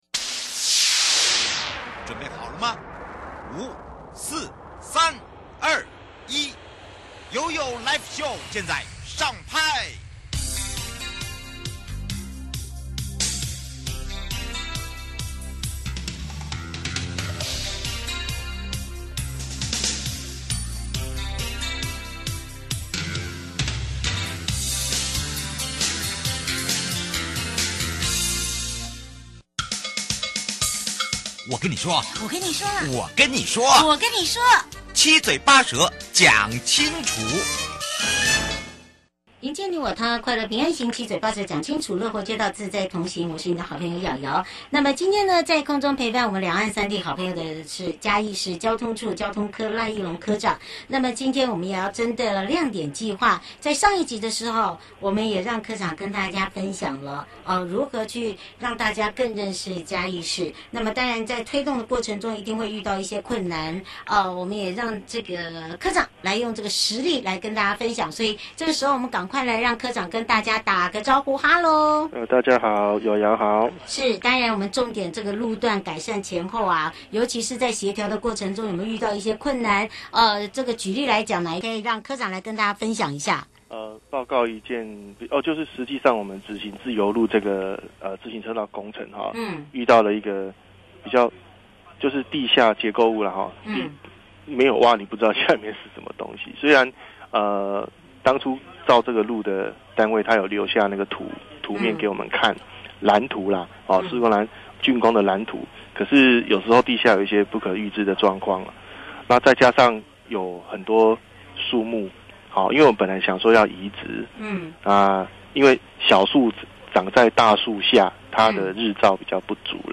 受訪者： 嘉義市-亮點計畫 (下集) 民眾對於這個亮點計畫改善前後有什麼反應或是在推動改善過程中有遇到什麼困難